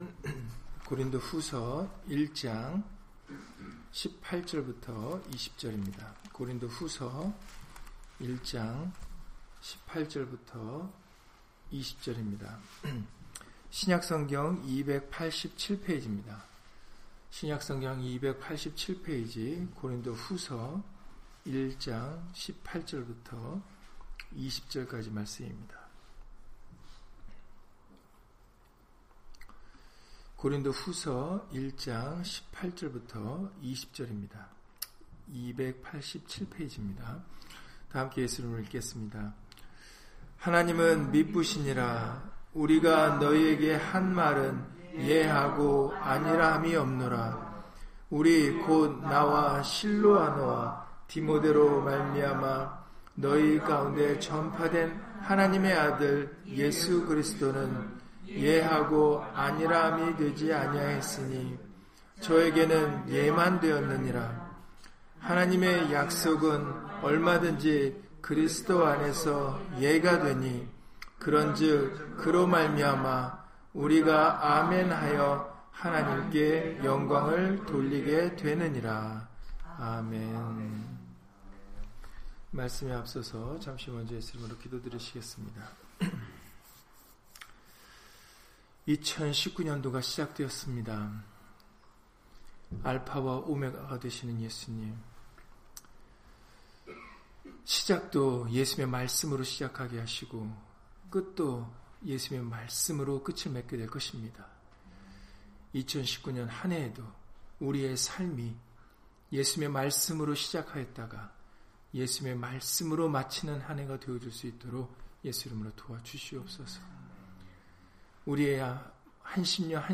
고린도후서 1장 18-20절 [송구영신 예배] - 주일/수요예배 설교 - 주 예수 그리스도 이름 예배당